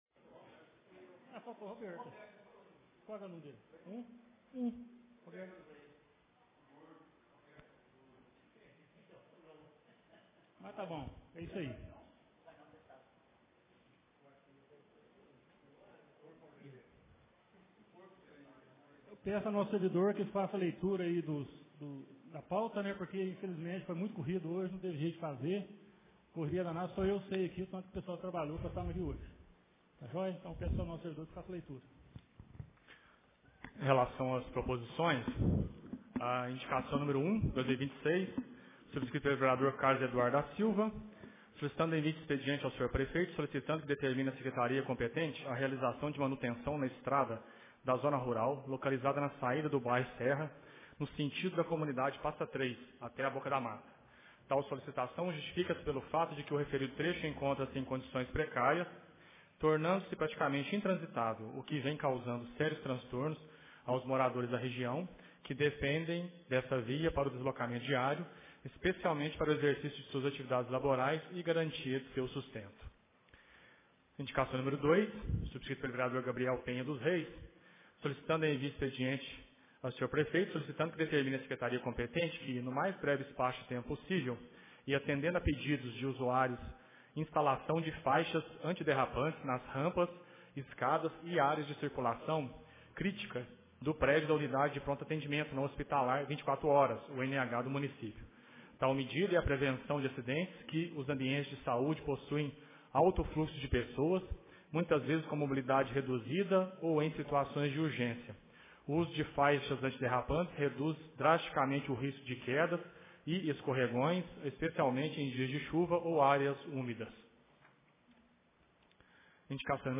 Ata da 1ª Reunião Ordinária de 2026